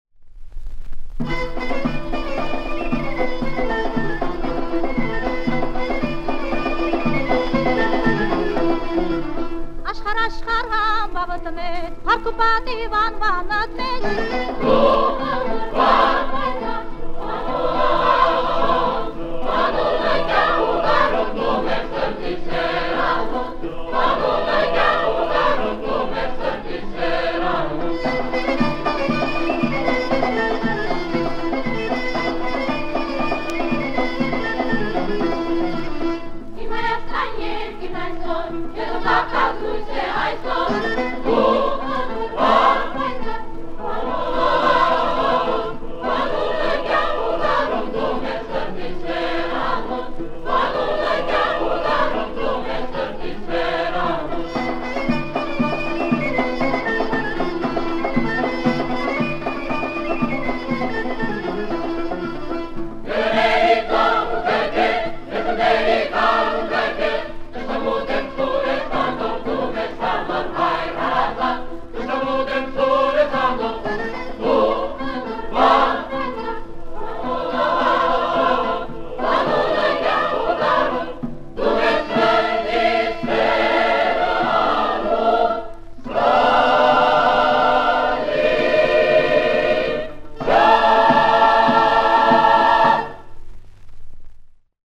Описание: Мелодичная песня о Вожде на армянском языке